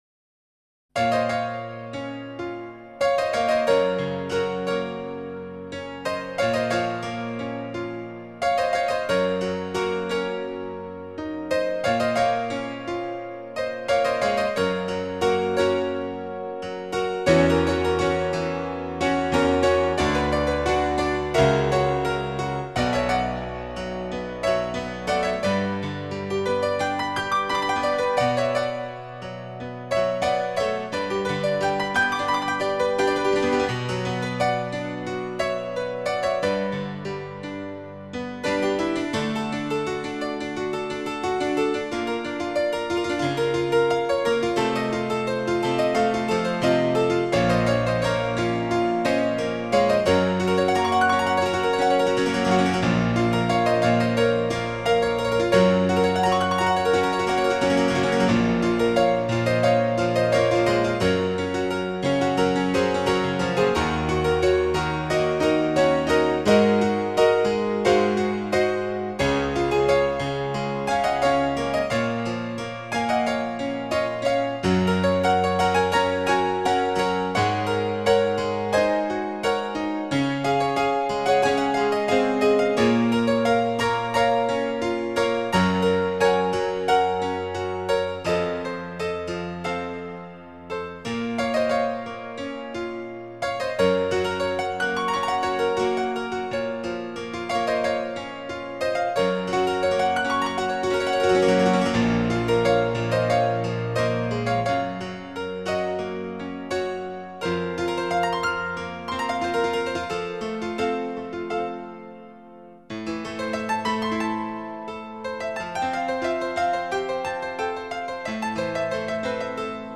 僅低音質壓縮 , 供此線上試聽
充滿寧靜 喜悅 生命力